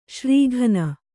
♪ śrī ghana